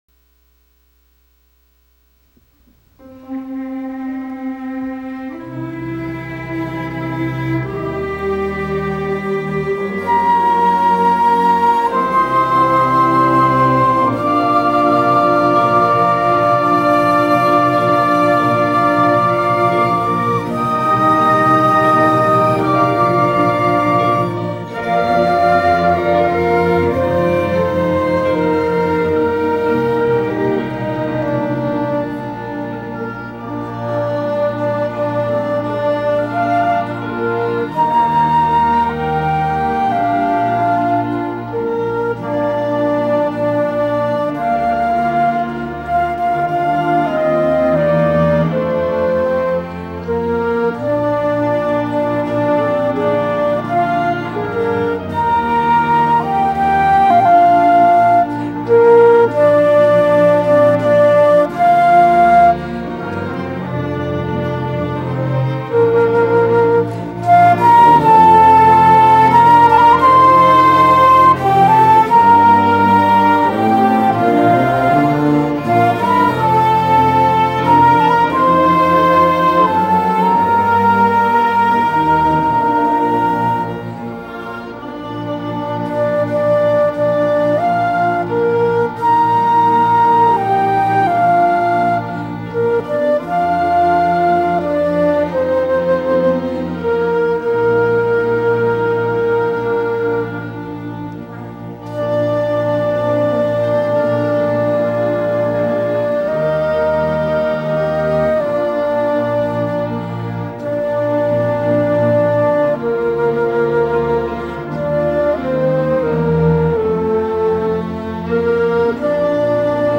“I’d Rather Have Jesus” ~ by Faith Baptist Orchestra*